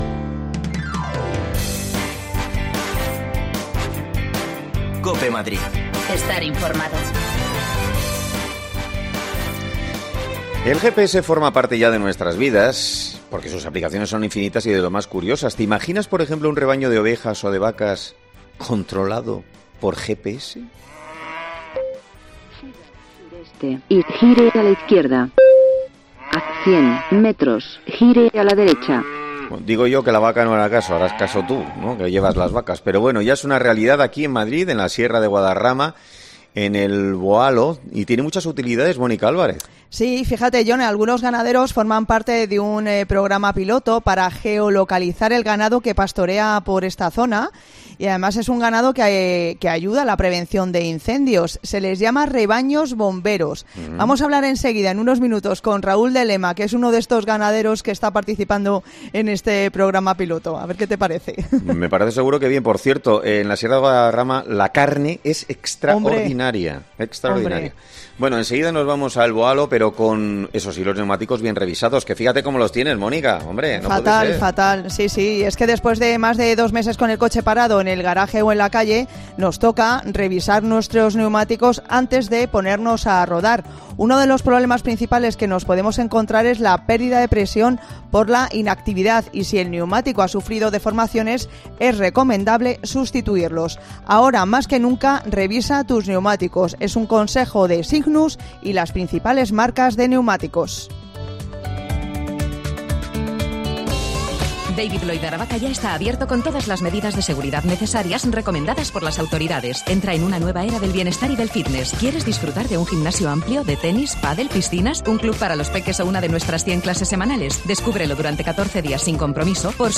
Escucha ya las desconexiones locales de Madrid de Herrera en COPE en Madrid y Mediodía COPE en Madrid.
Las desconexiones locales de Madrid son espacios de 10 minutos de duración que se emiten en COPE, de lunes a viernes.